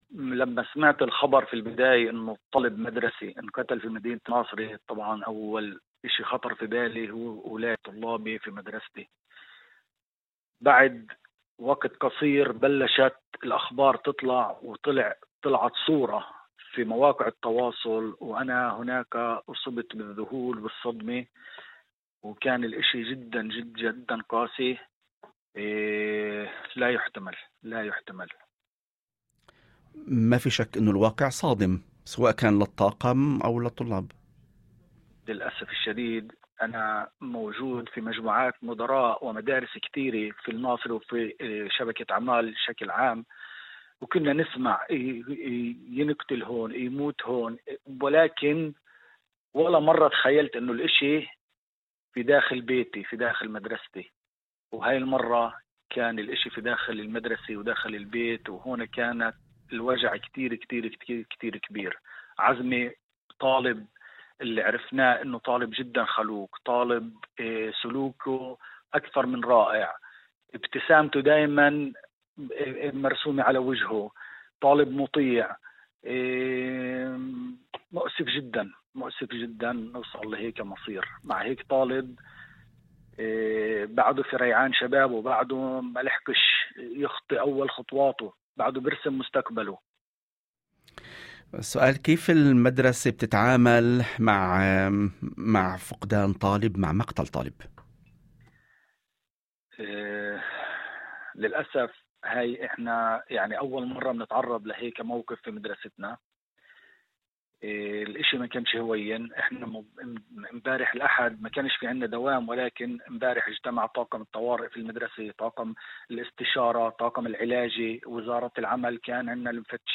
وأضاف في مداخلة هاتفية ضمن برنامج "يوم جديد"، على إذاعة الشمس، أن الصدمة كانت مضاعفة عندما تبين أن الطالب هو أحد طلاب المدرسة، قائلا: "لم أتخيل يوما أن يحدث هذا داخل بيتي، داخل مدرستي، الوجع كان كبيرا جدا".